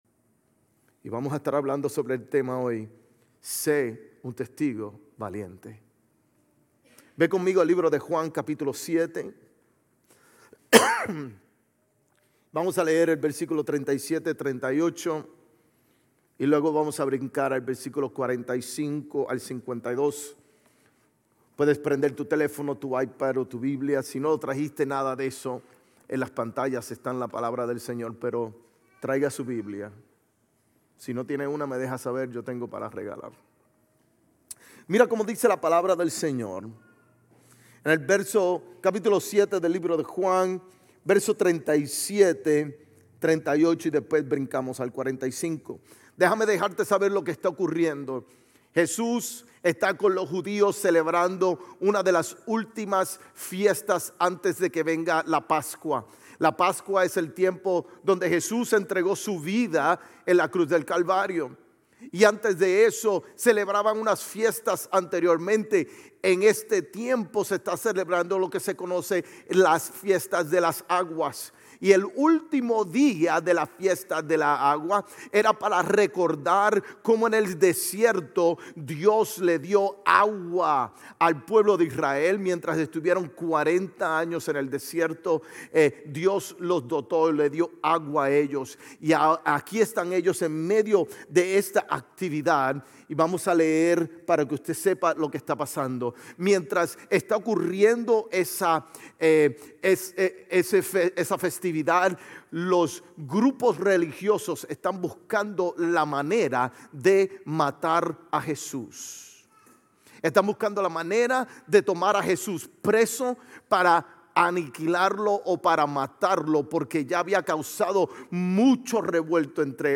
GCC-GE-November-19-Sermon.mp3